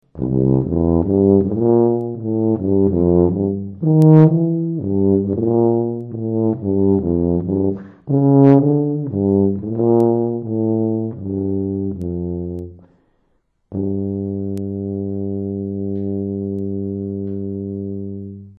tubariff.mp3